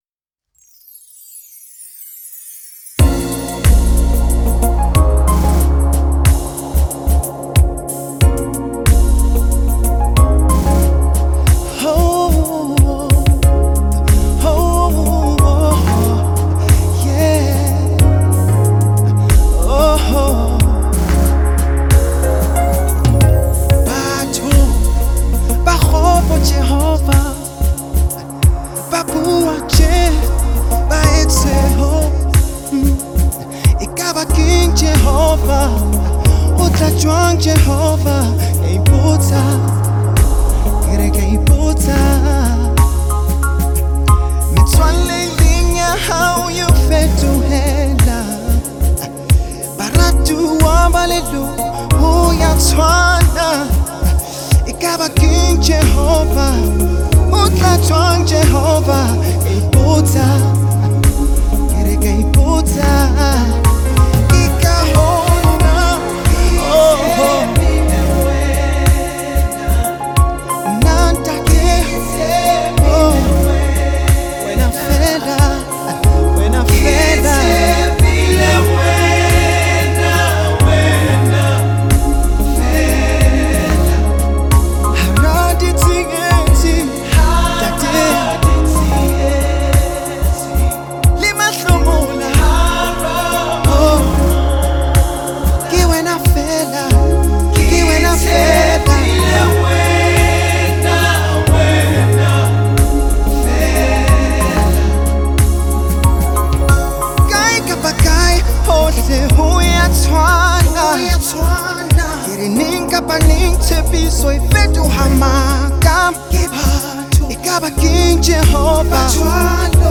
Genre : SA Gospel